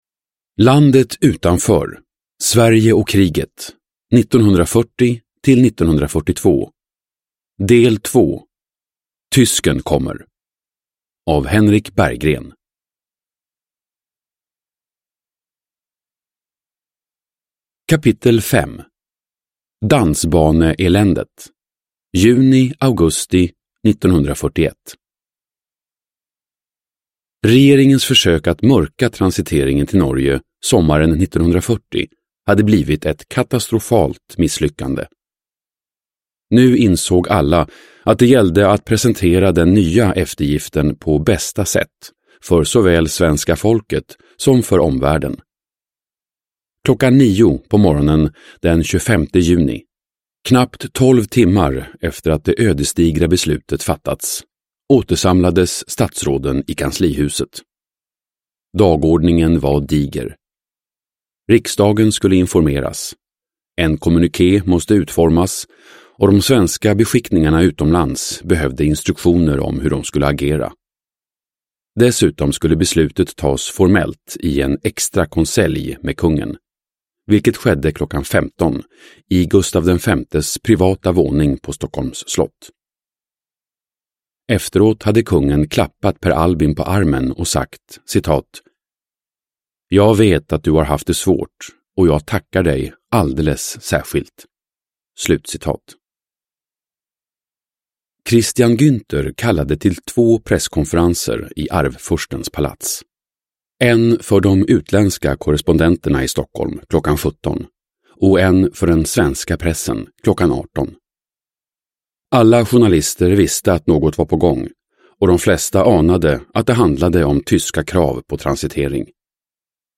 Landet utanför : Sverige och kriget 1940-1942. Del 2:2, Tysken kommer! – Ljudbok – Laddas ner